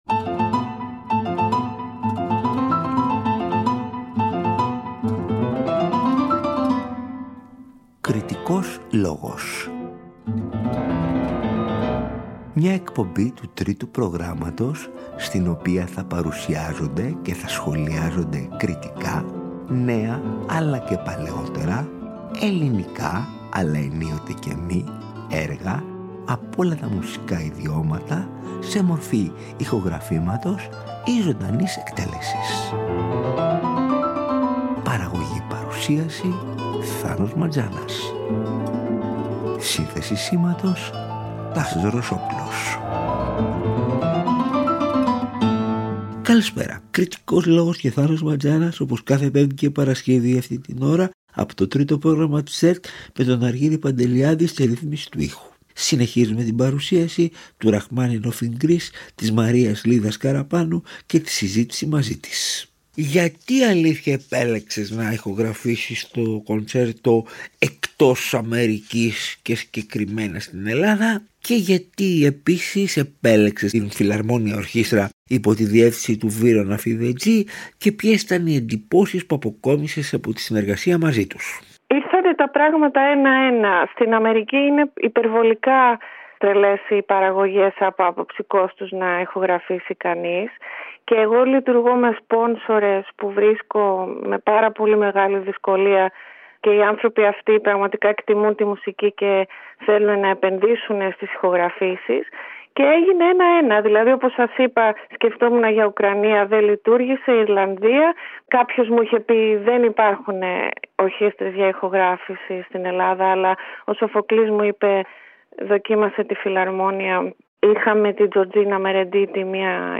μιλά στον κριτικό μουσικής